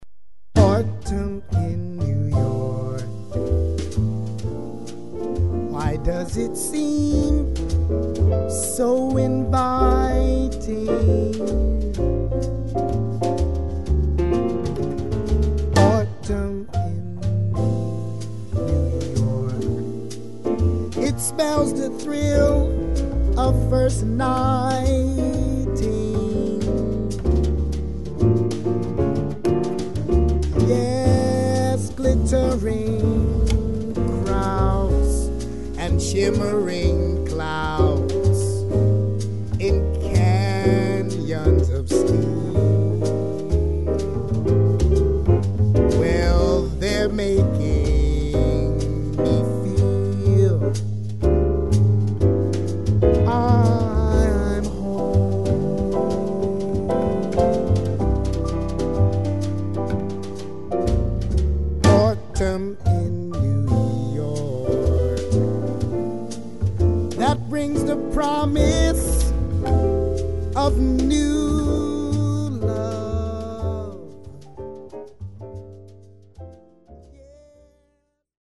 vocals
acoustic, electric bass
drums